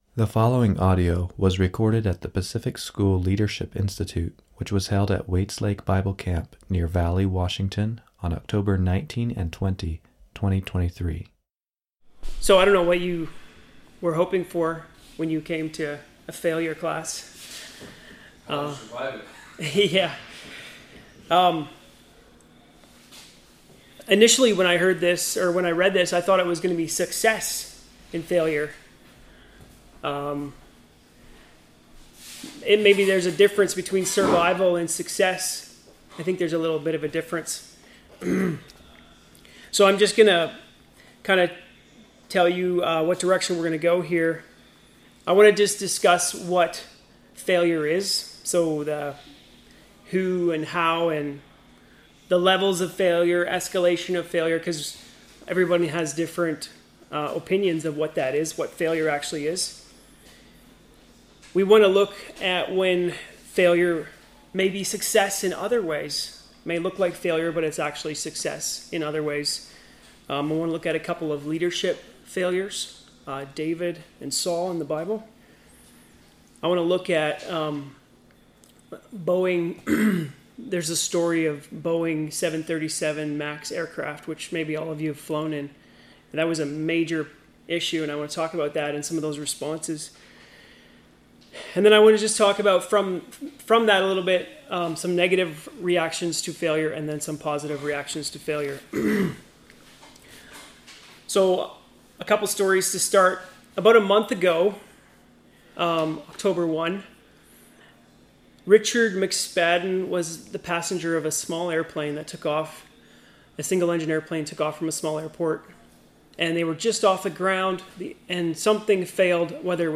Home » Lectures » Surviving Failure